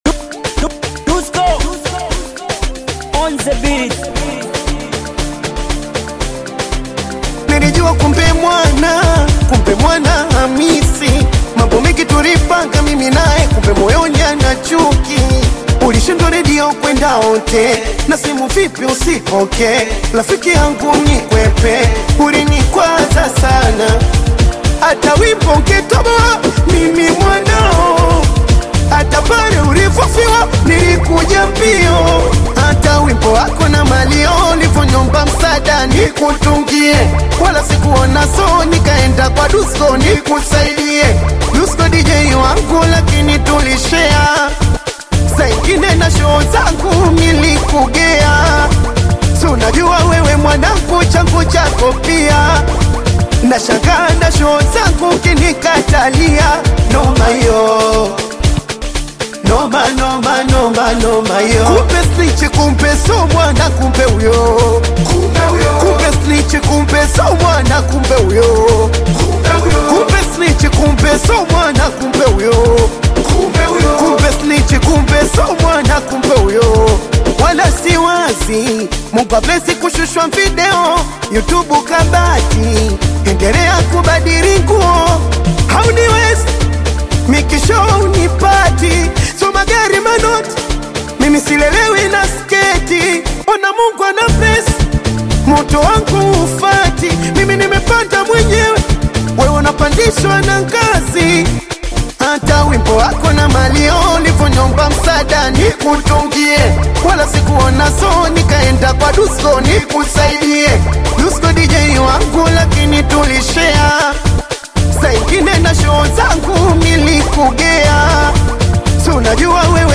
high-energy